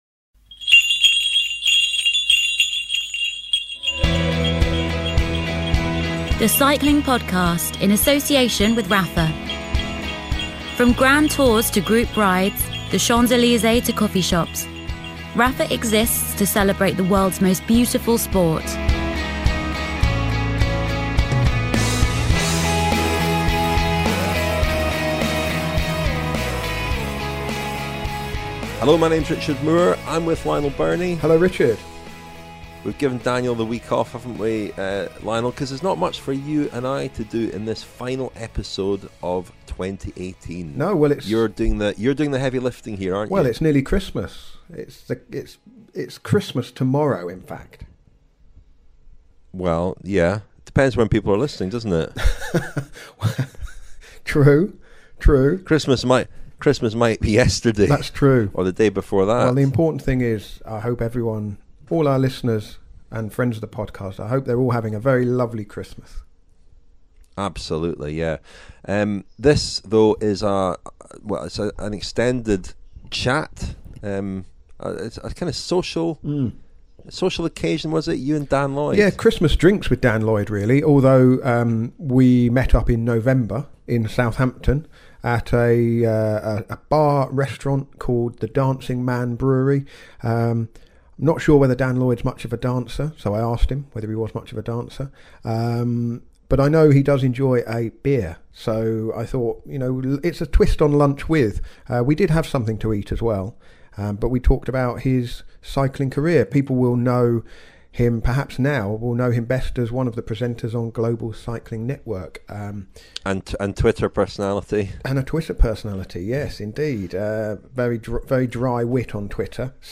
Okay, these were early Christmas drinks – recorded in mid-November but the Christmas decorations were up.